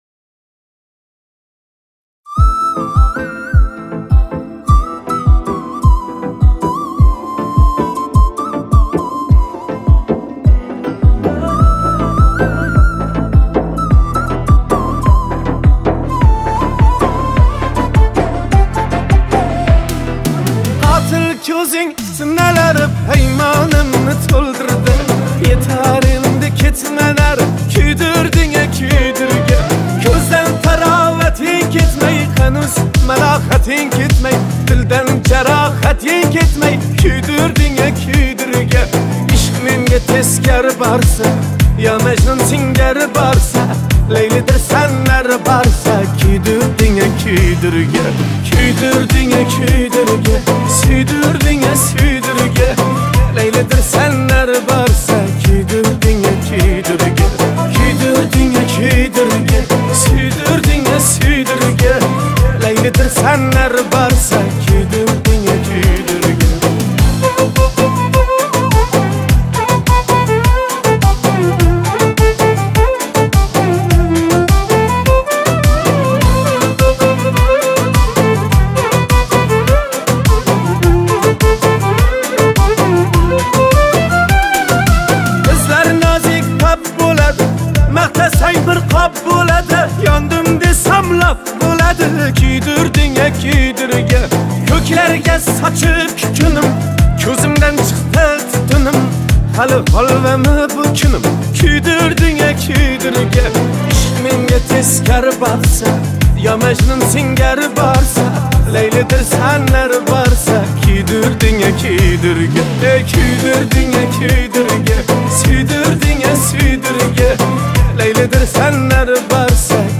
• Категория: Узбекская музыка